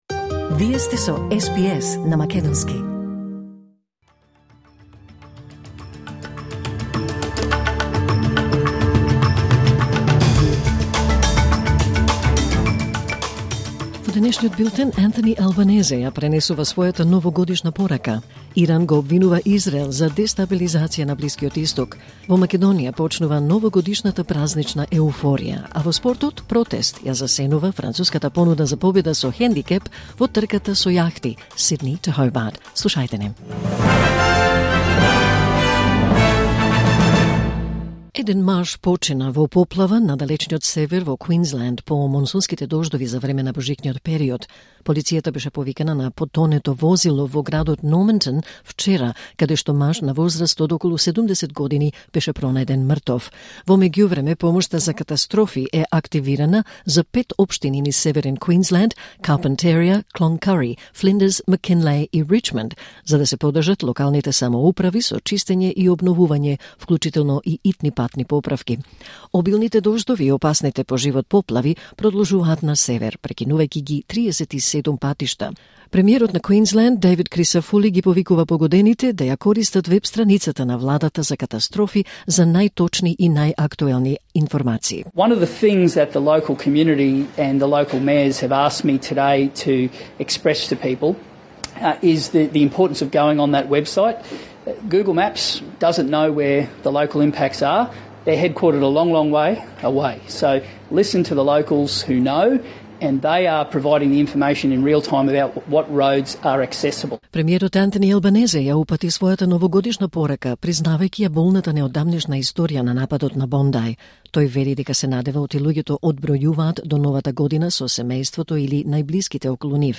Вести на СБС на македонски 31 декември 2025